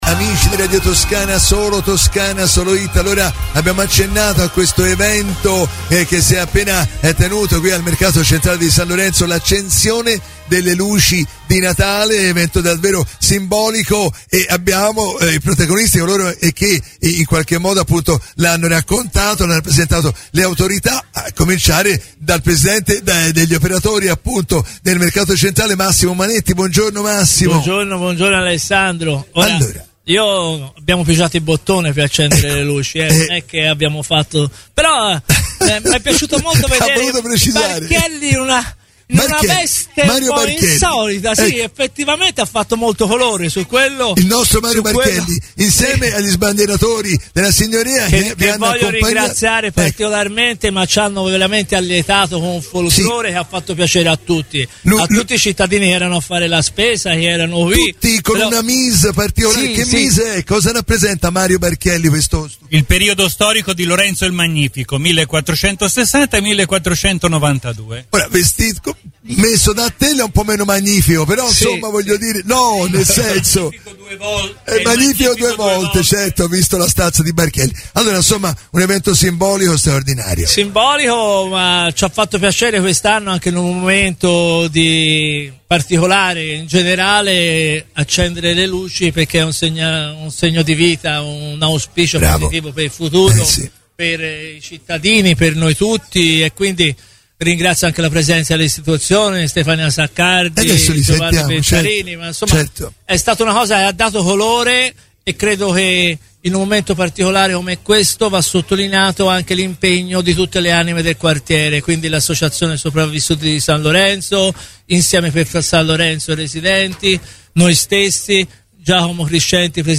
03 Dicembre 2022 - Cerimonia Luci Natale Mercato Di San Lorenzo